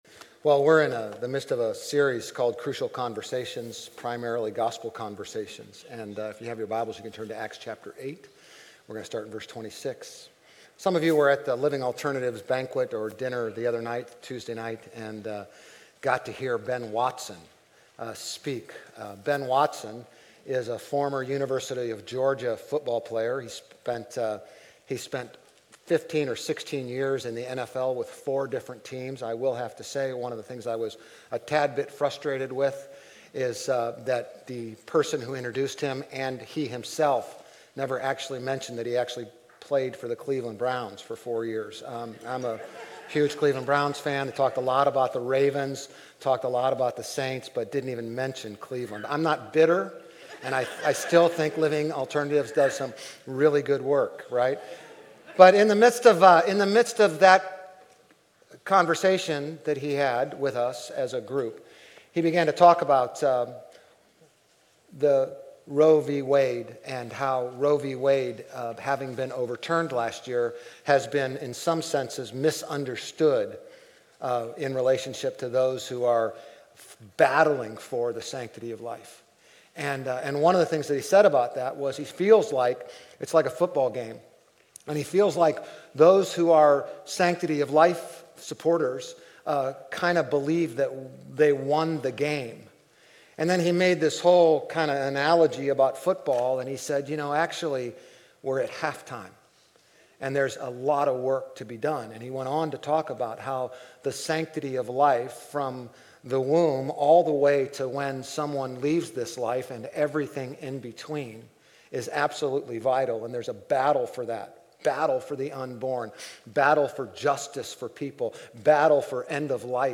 Grace Community Church Old Jacksonville Campus Sermons Crucial Conversations: Acts 8:26-40 Oct 15 2023 | 00:36:17 1x 00:00 / 00:36:17 Subscribe Share RSS Feed Share Link Embed
GCC-OJ-October-15-Sermon.mp3